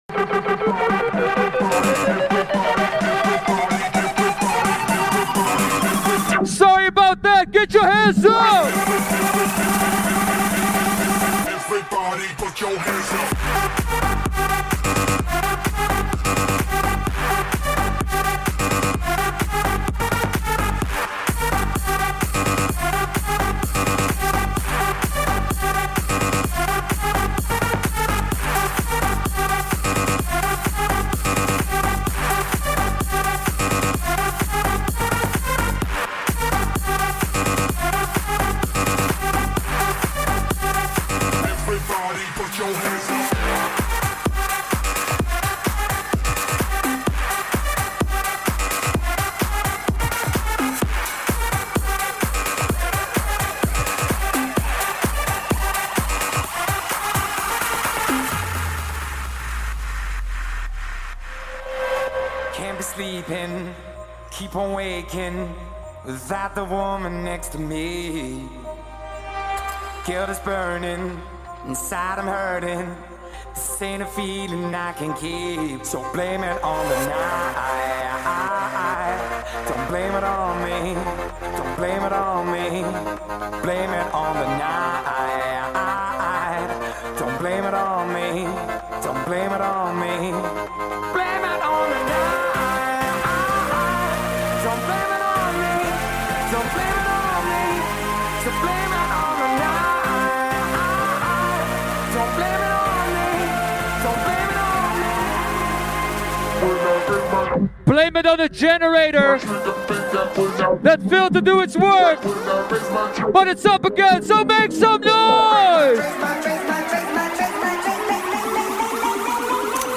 Liveset/DJ mix